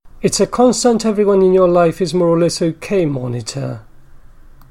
The clause is used attributively and I would venture that the accent goes on the last lexical word of it while the second element –that is, the noun– is deaccented.